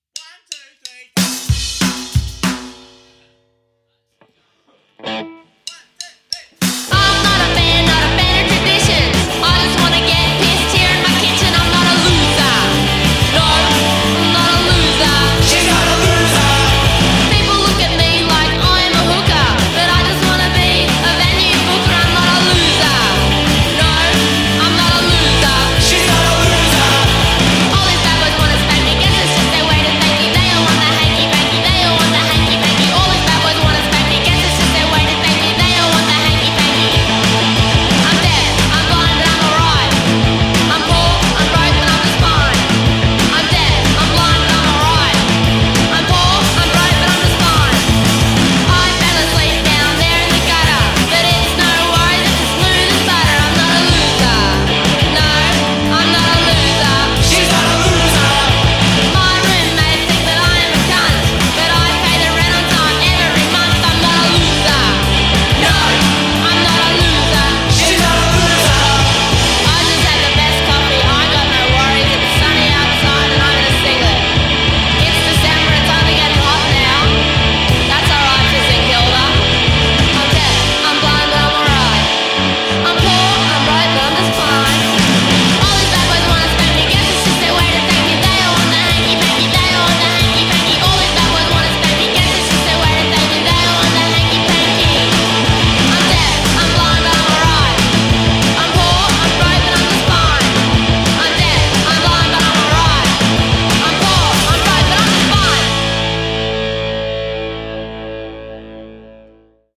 punk/rock band